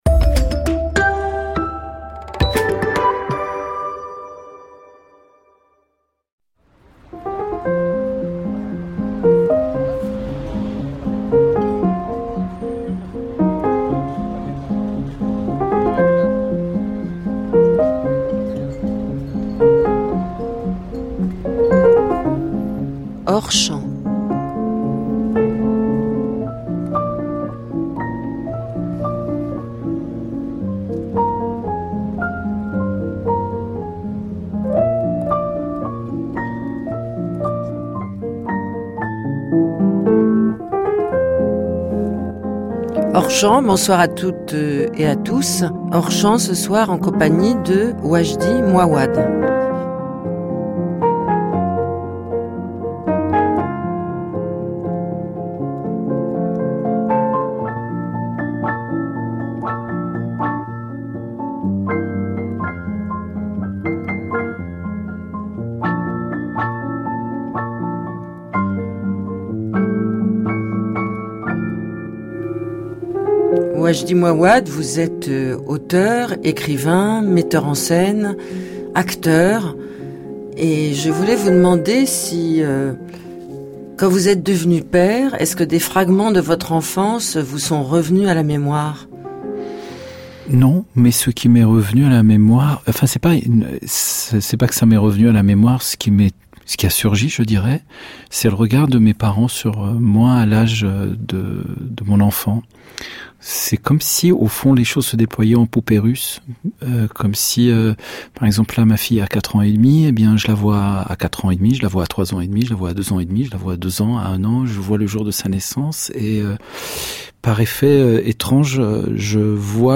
Entretien avec Laure Adler sur France-Culture.